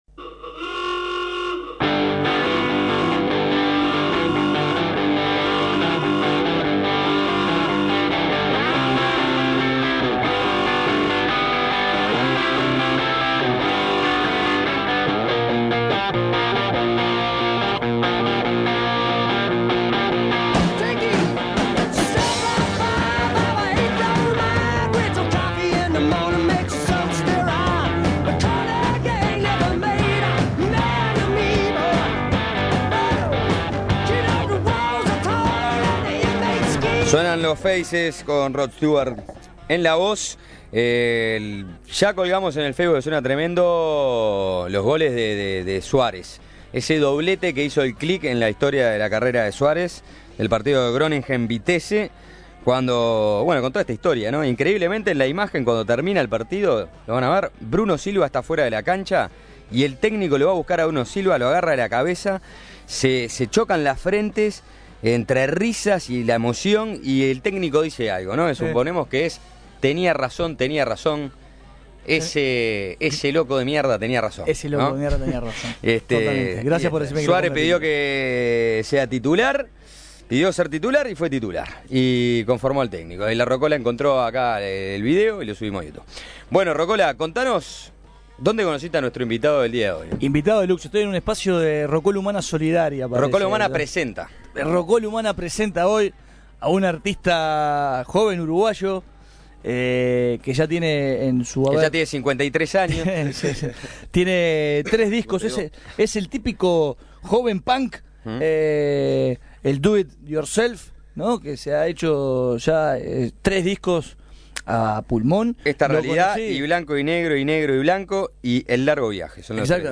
tocó en vivo una de sus canciones
hizo un par de versiones de clásicos del rock.